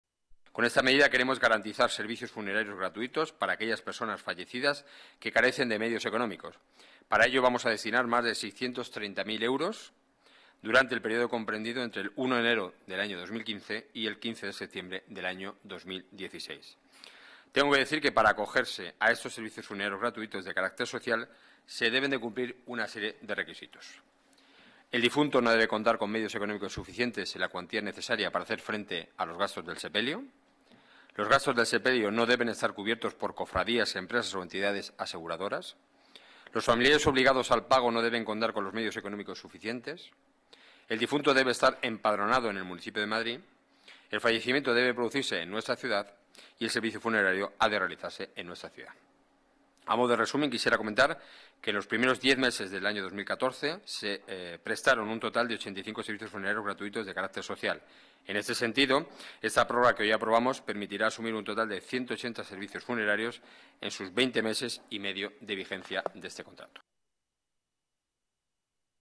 Nueva ventana:Declaraciones portavoz Gobierno municipal, Enrique Núñez: Servicios Funerarios sociales gratuitos